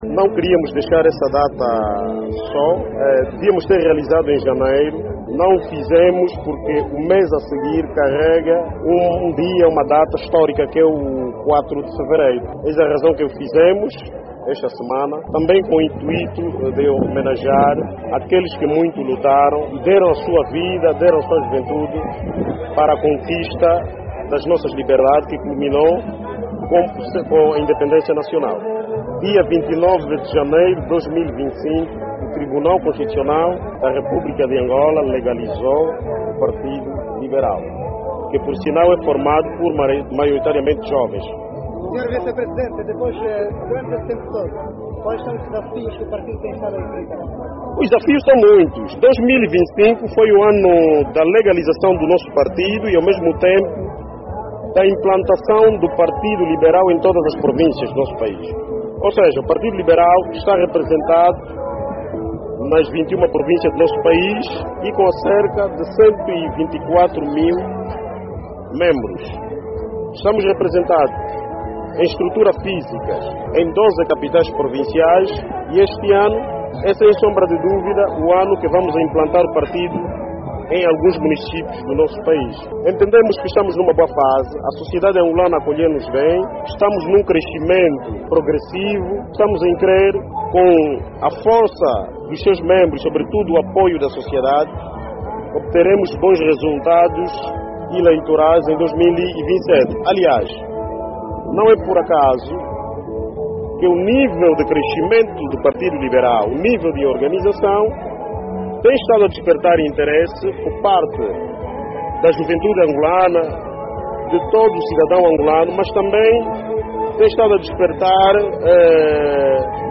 As alegações foram feitas durante um almoço de confraternização realizado no município do Cazenga, em Luanda, que reuniu membros da direcção, militantes e simpatizantes do Partido Liberal, em alusão ao primeiro aniversário do partido, tendo apontado a UNITA como responsável pelas supostas acções.